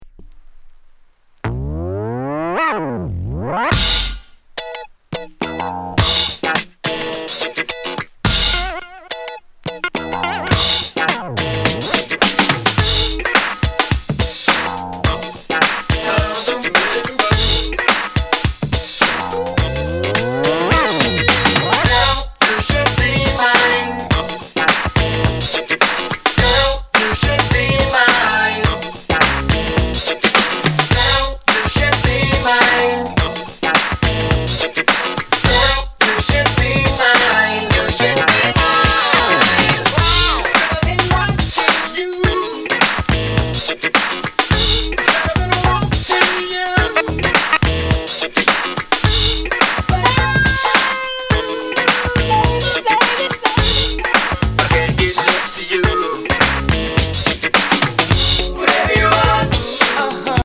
Genre: Funk